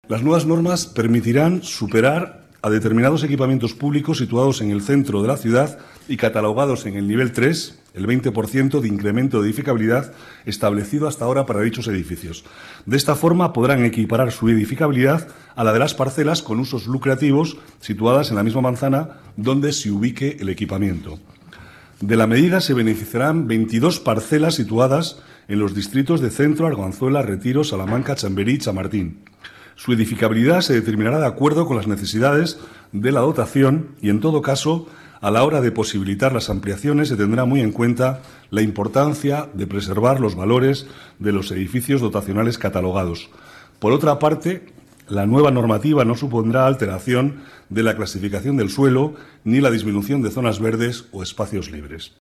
Nueva ventana:Declaraciones del vicealcalde, Manuel Cobo, sobre las normas urbanísticas